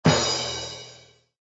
SZ_MM_cymbal.ogg